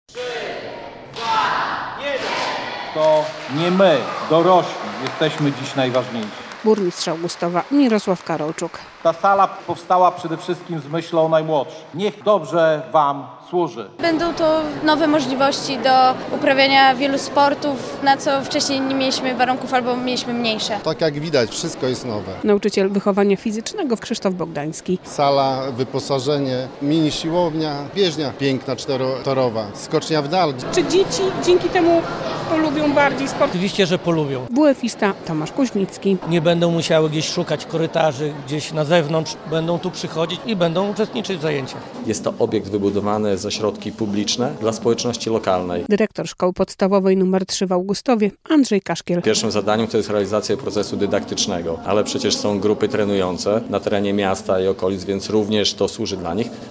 Otwarcie bloku sportowego przy SP3 w Augustowie - relacja
Na uroczystym otwarciu (27.02) nie zabrakło władz miasta, lokalnych samorządowców, nauczycieli i uczniów.